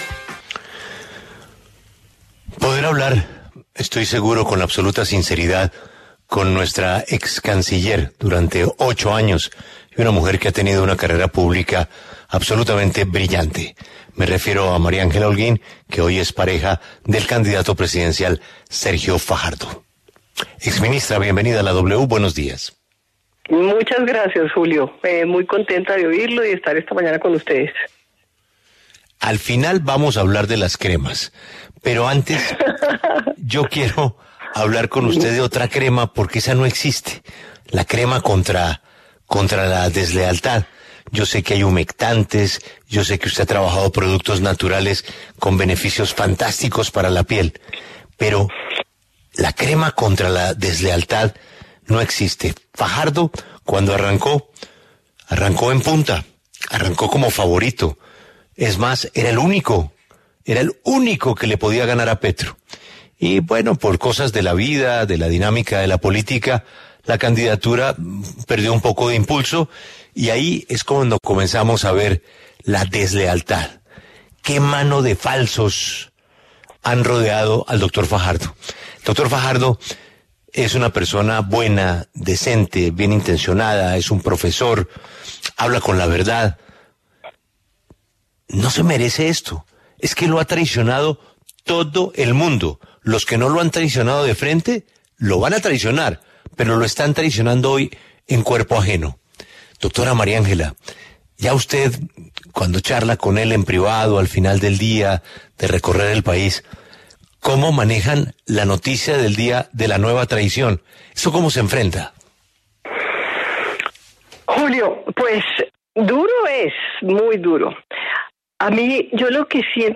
En diálogo con La W, la excanciller María Ángela Holguín conversó sobre la candidatura presidencial de Sergio Fajardo y su camino a las urnas el próximo 29 de mayo.